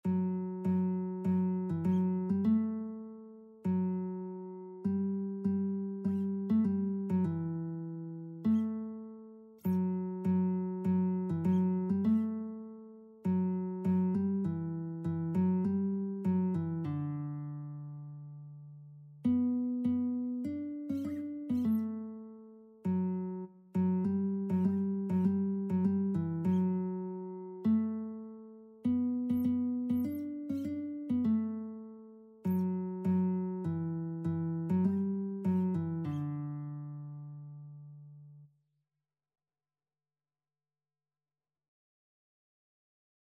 Christian Christian Lead Sheets Sheet Music Shall We Gather by the River
4/4 (View more 4/4 Music)
D major (Sounding Pitch) (View more D major Music for Lead Sheets )
Traditional (View more Traditional Lead Sheets Music)